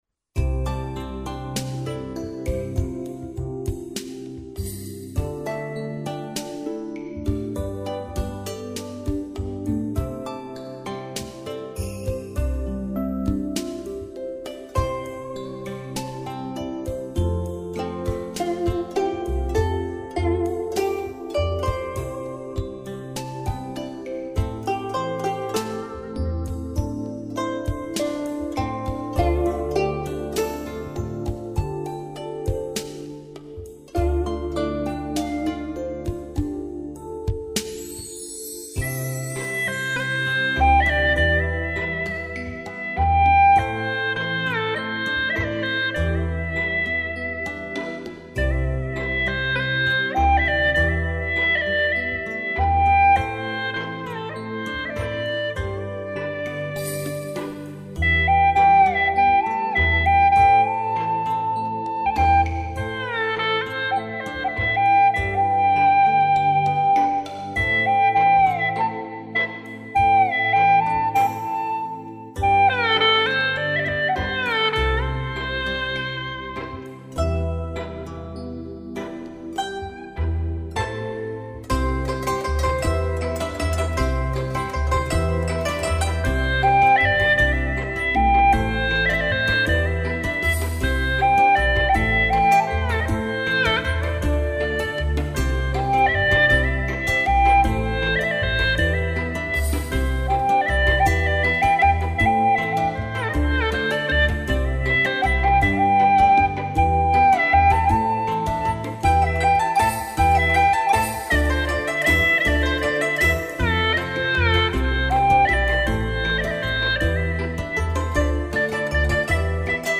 调式 : C 曲类 : 独奏